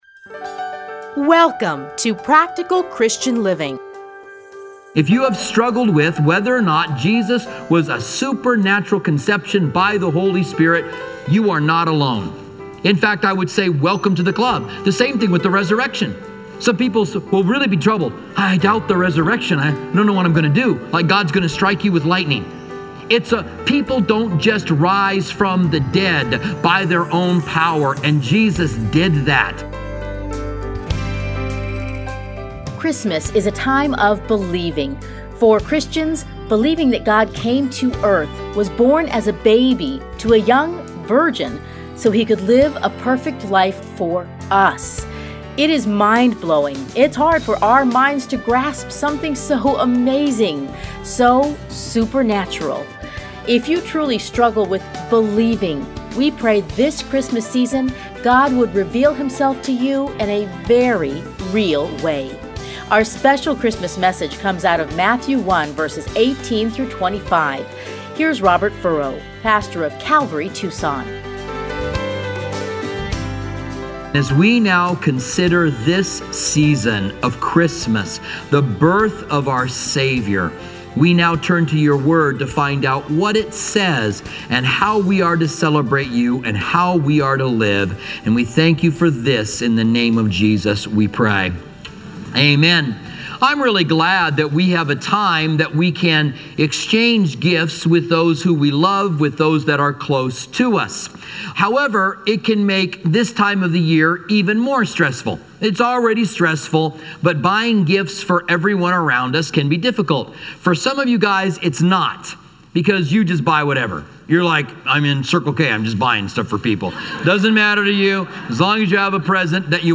Listen here to part 1 of his 2018 Christmas message.